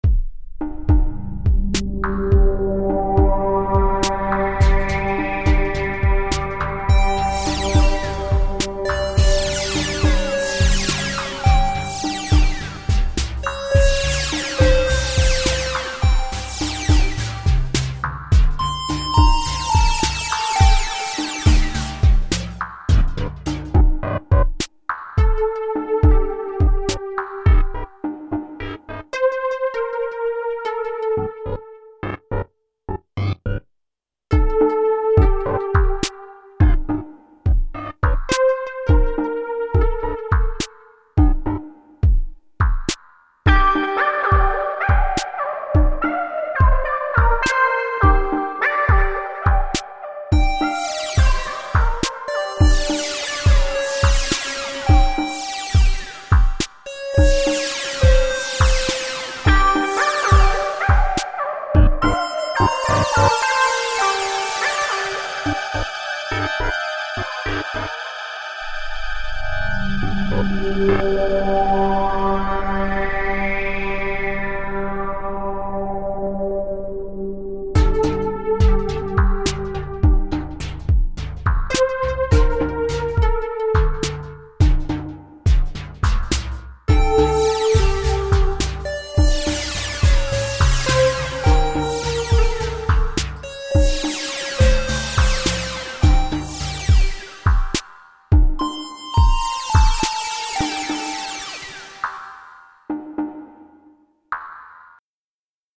60 new Presets for quick acceess: Typical Lead Synth, Pad, Synth Bass, Percussives and Special Effects sounds, that is what the original Minimoog is famous for since decades.
During sound production, an original Minimoog was used standing to the side to create sounds for tje Creamware Minimax ASB to ensure the typical and very similar character.